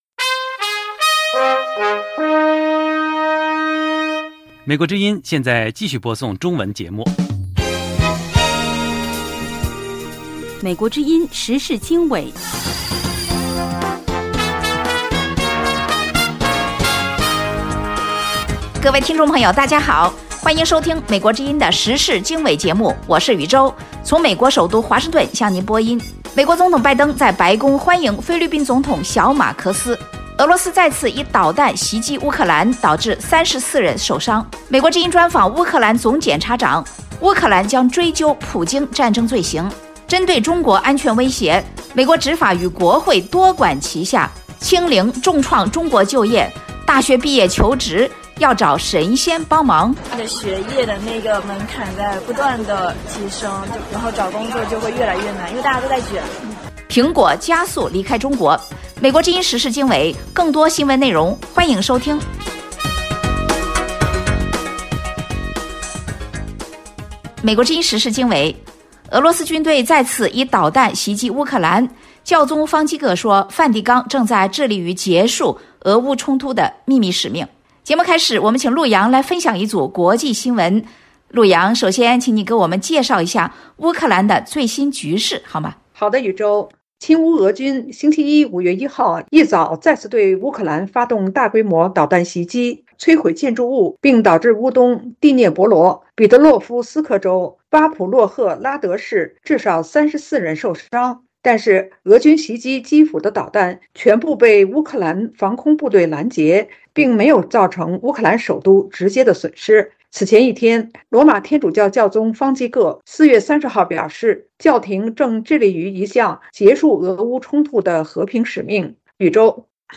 2/俄罗斯导弹再次袭击乌克兰，34人受伤。3/美国之音专访乌克兰总检察长，乌克兰将追究普京战争罪行。4/针对中国安全威胁，美执法与国会多管齐下。